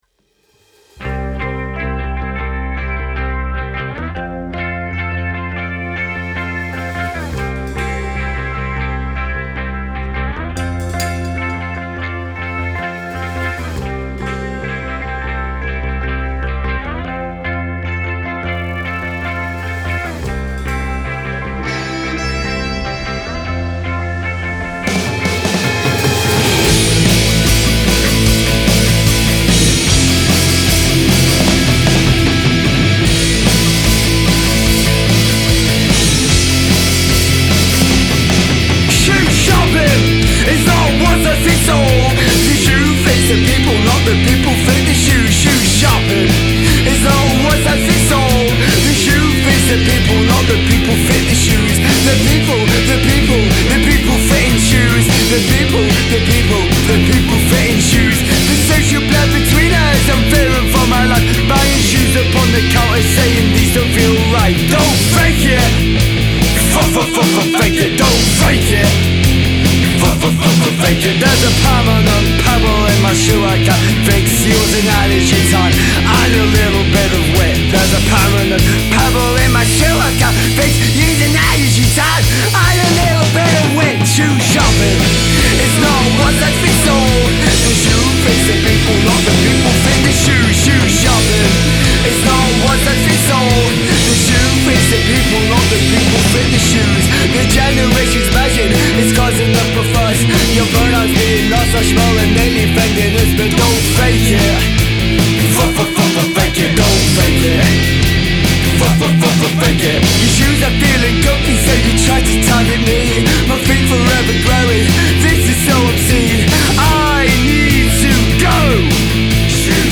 a post punk banger
This raw and chaotic punk anthem
with its searing guitar lines